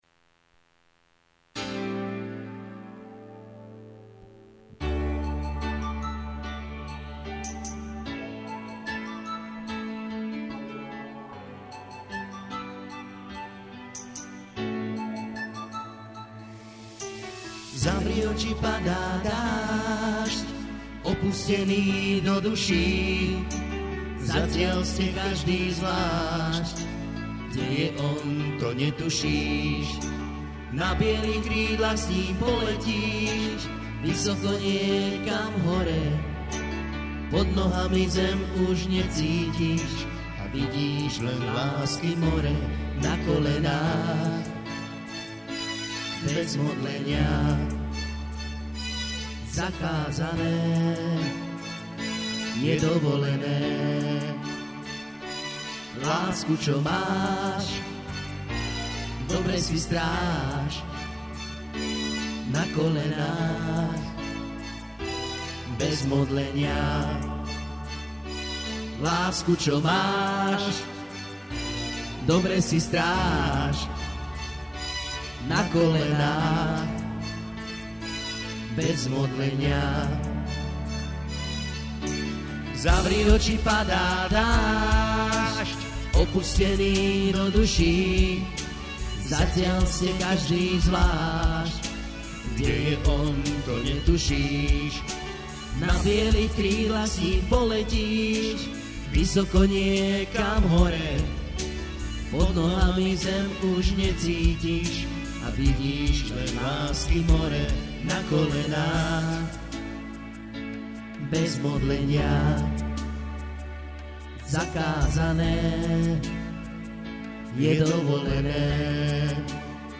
spev
duo